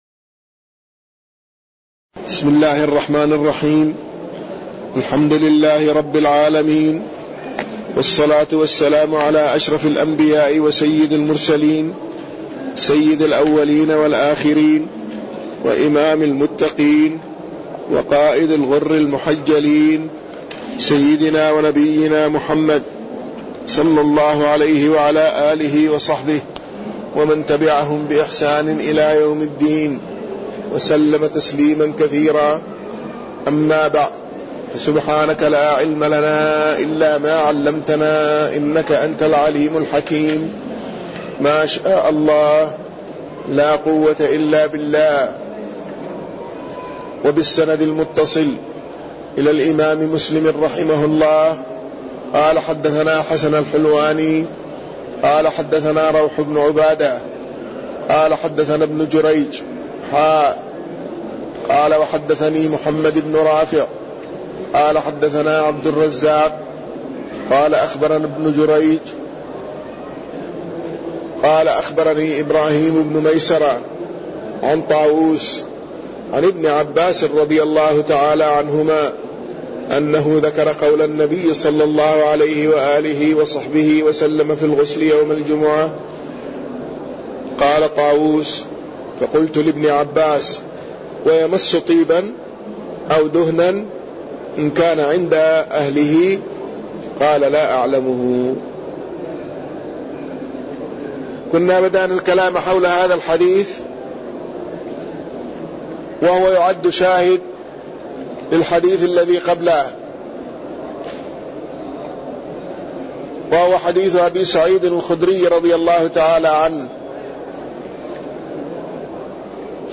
تاريخ النشر ١٨ رجب ١٤٣١ هـ المكان: المسجد النبوي الشيخ